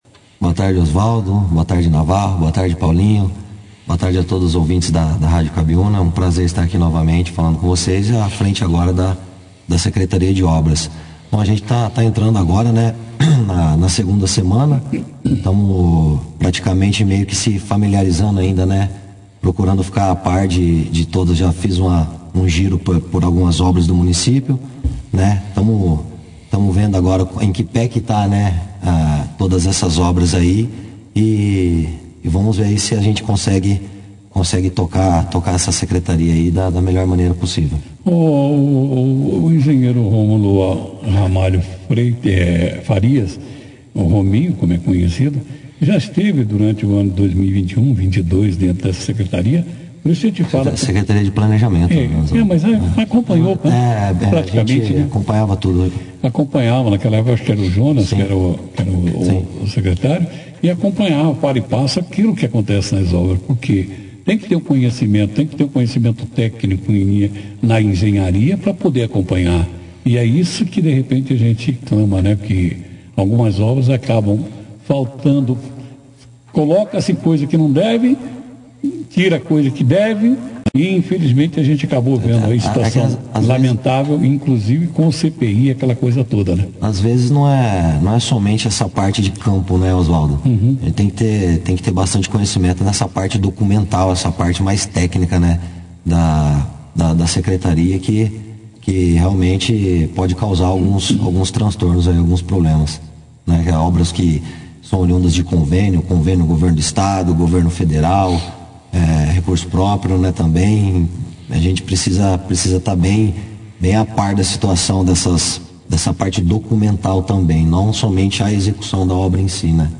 O novo Secretário de Obras de Bandeirantes, o engenheiro Romulo Ramalho Faria, (foto), participou da 2ª edição do jornal Operação Cidade. Na entrevista Rominho, falou de suas perspectivas e planos à frente da pasta.